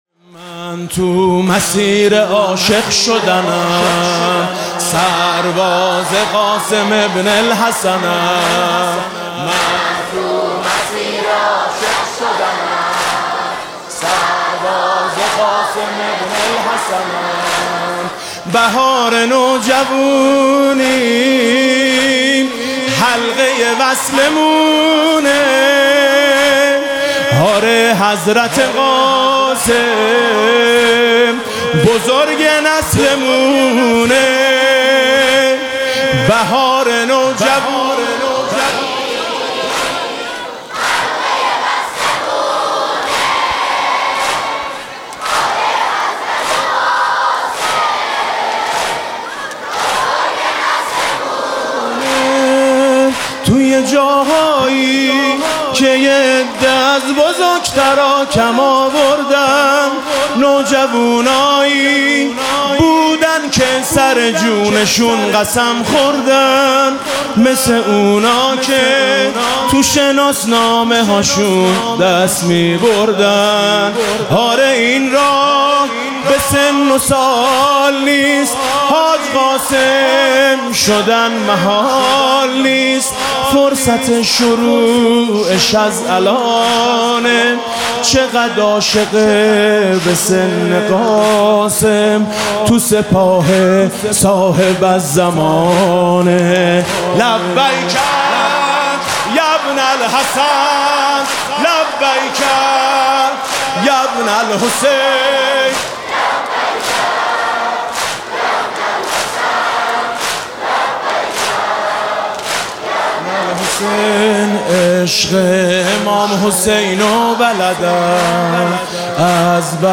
هیأت نوجوانان در آستانه ماه محرم
با مداحی: حاج میثم مطیعی
مداحی نوجوانان: سرباز قاسم [صوتی]
واقعا متناسب بانوجوونا بود،خیلی روان و آروم